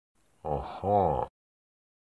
Звуки человека, аха
• Качество: высокое
Подозрительное аха мужчины